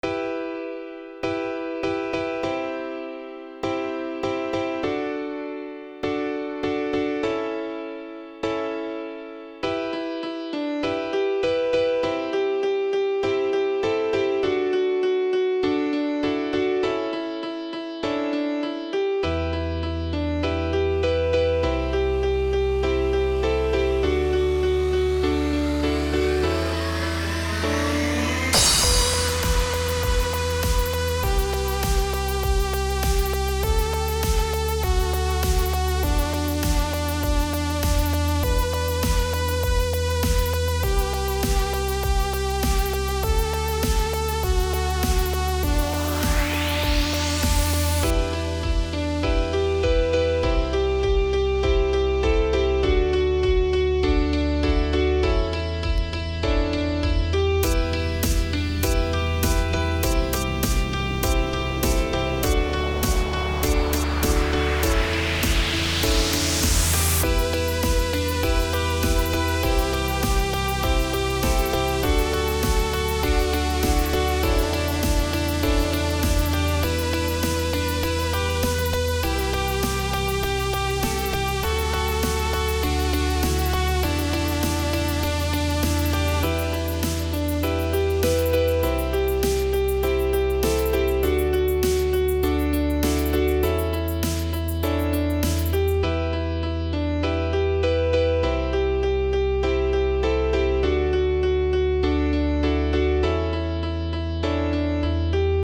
how do i fix so my music doesnt sound so boring and the same melody